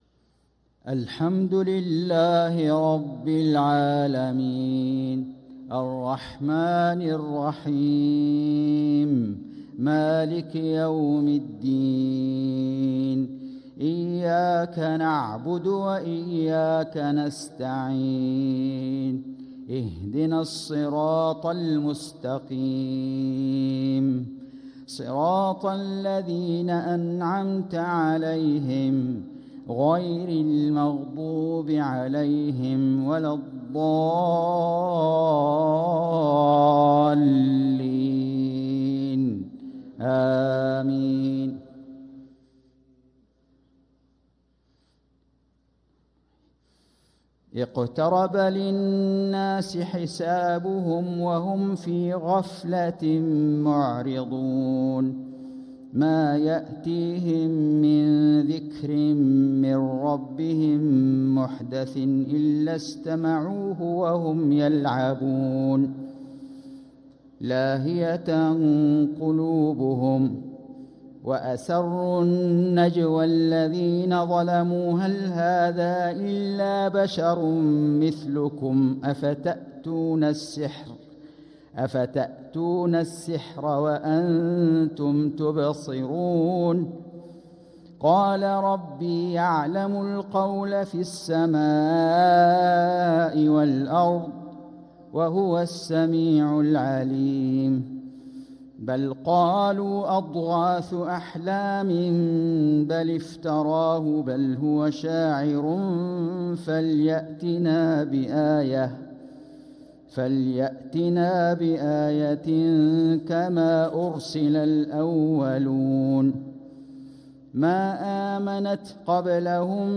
صلاة العشاء للقارئ فيصل غزاوي 10 ربيع الآخر 1446 هـ